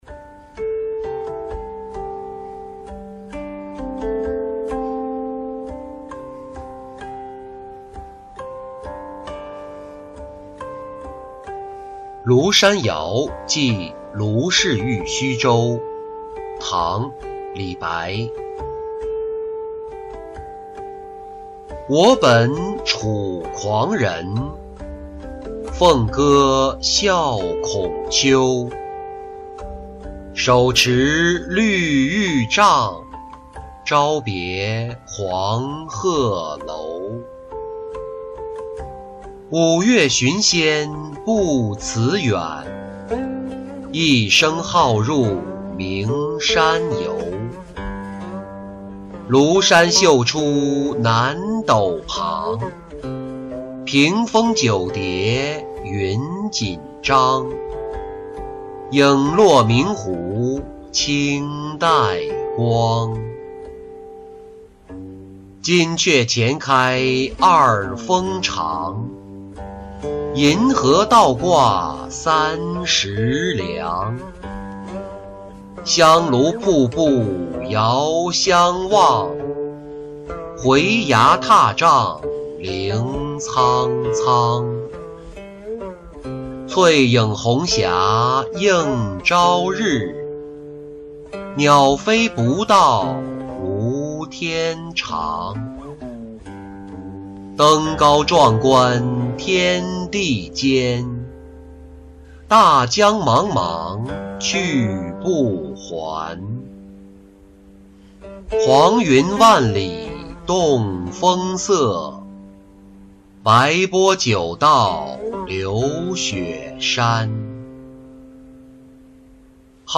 秋风引-音频朗读